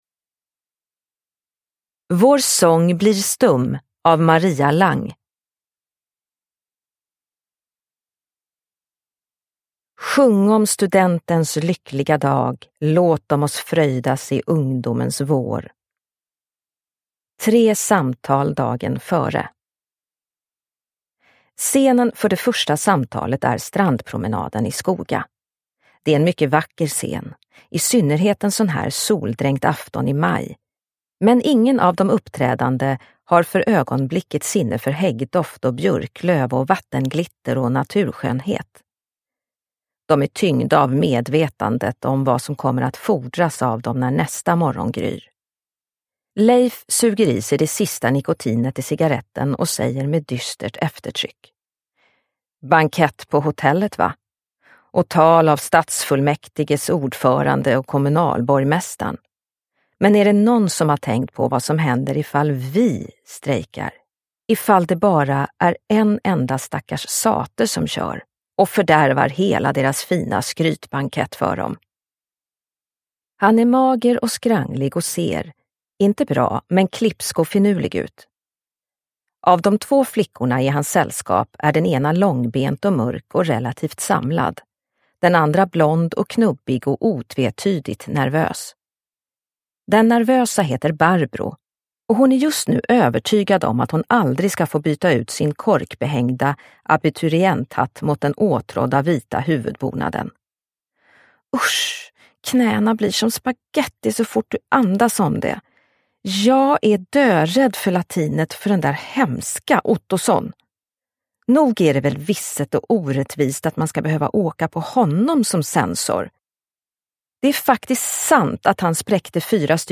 Vår sång blir stum – Ljudbok